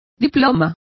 Complete with pronunciation of the translation of diplomas.